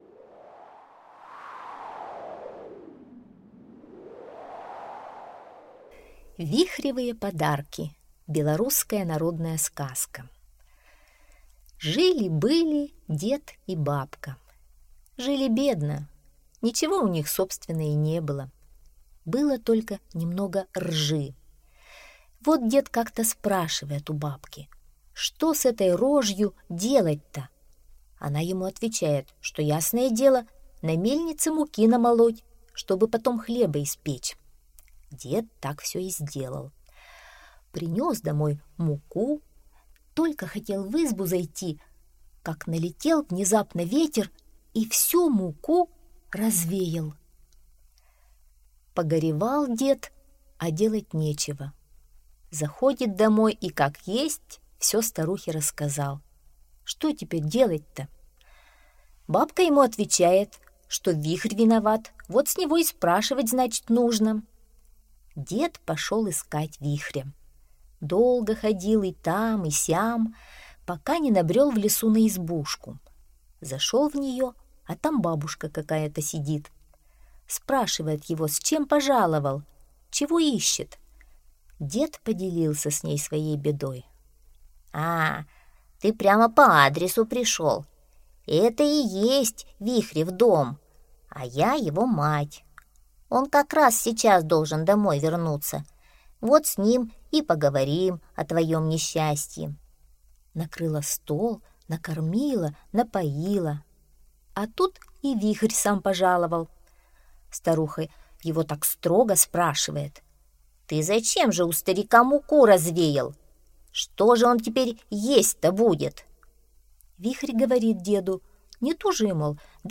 Вихревые подарки - белорусская аудиосказка - слушать онлайн